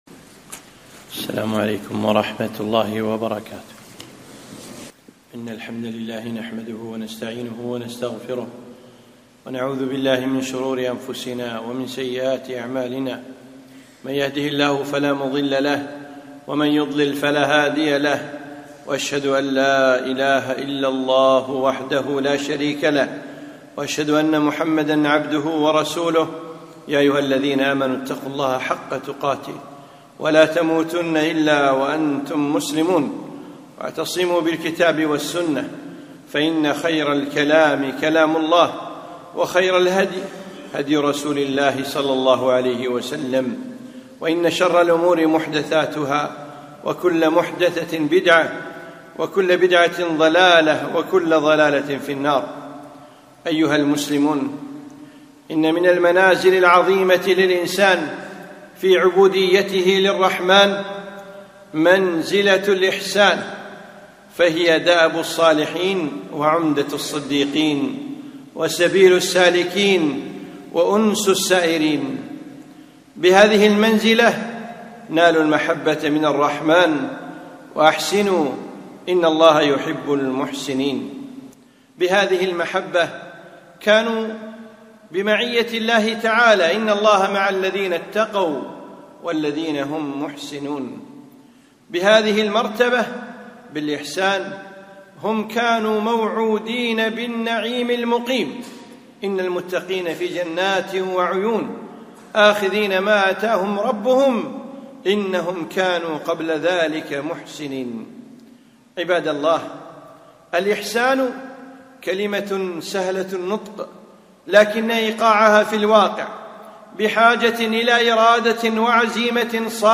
خطبة - مقام الإحسان